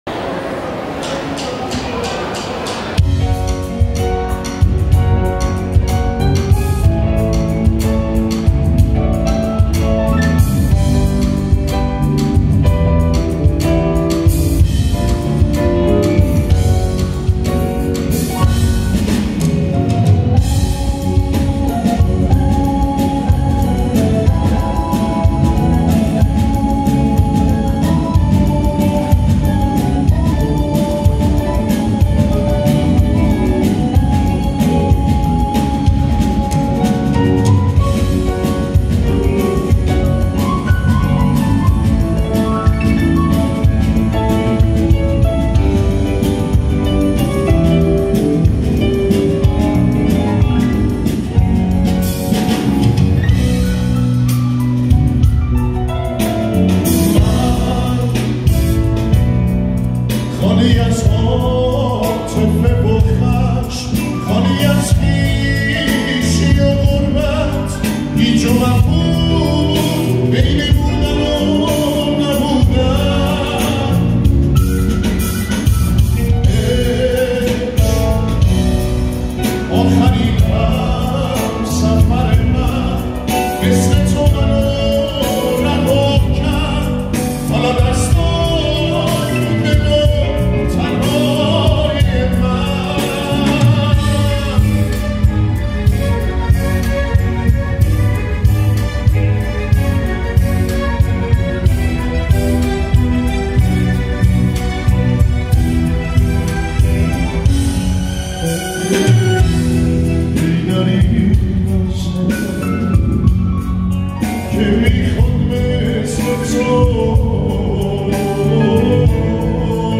گروه ارکستری